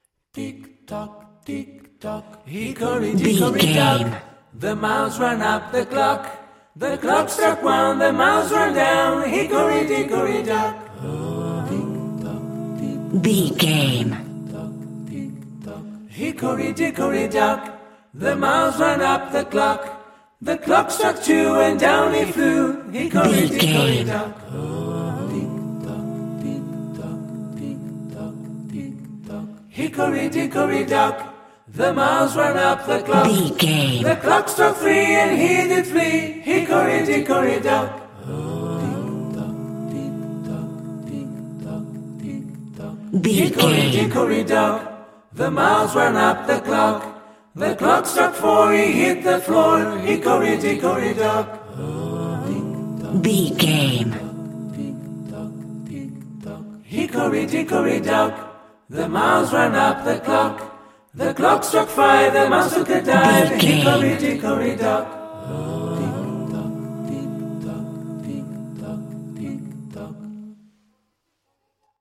Acapella
Ionian/Major
Fast
nursery rhymes
fun
childlike
cute
happy